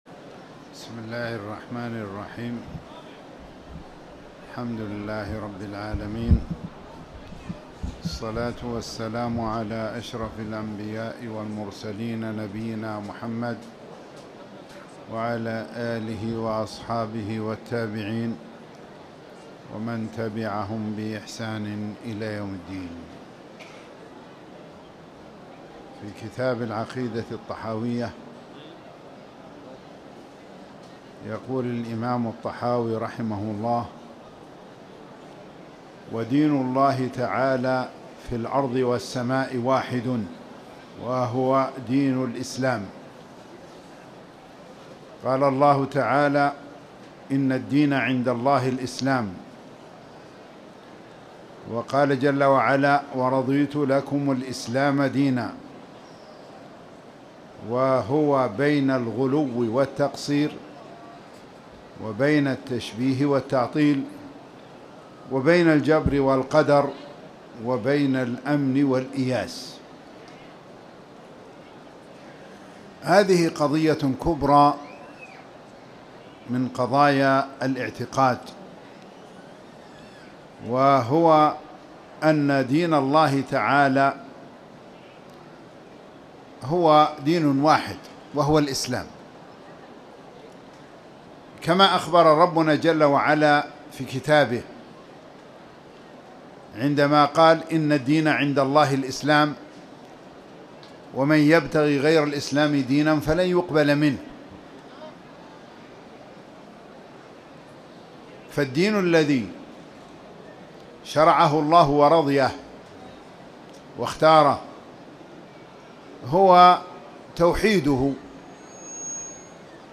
تاريخ النشر ٢٧ رجب ١٤٣٨ هـ المكان: المسجد الحرام الشيخ